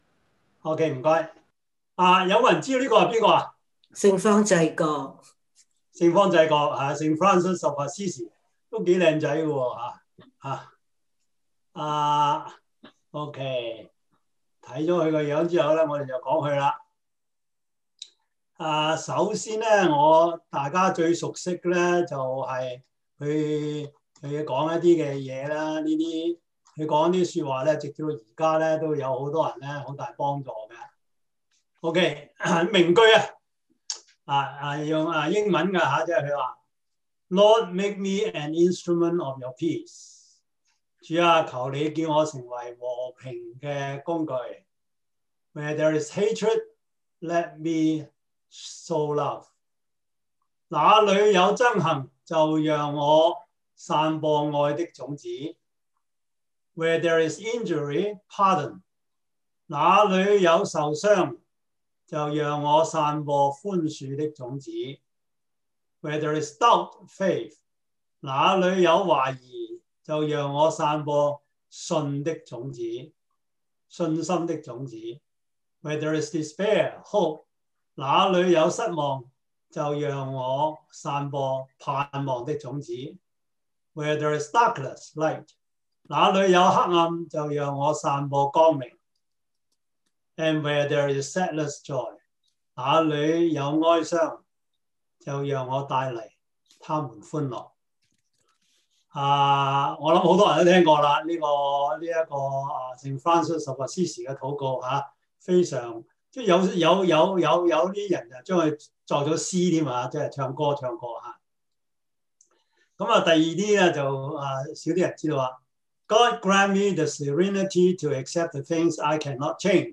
Series: 中文主日學